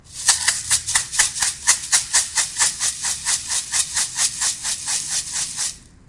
大米
描述：使用Blue Yeti麦克风录制
标签： 沙子 大米 振动筛
声道立体声